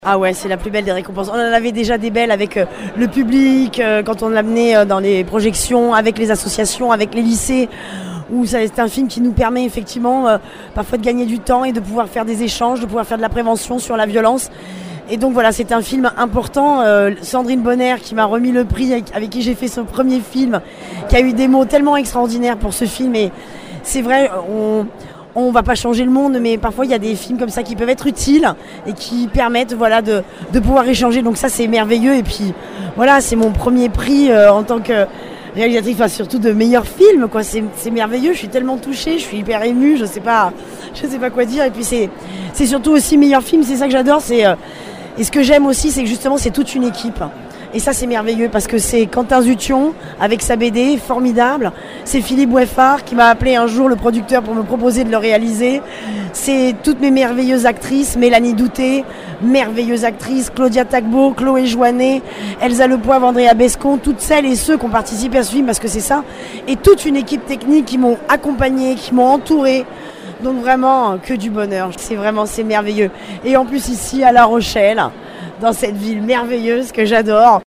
Le Festival de la fiction de La Rochelle s’est refermé samedi soir, avec la remise des récompenses sur la grande scène de La Coursive.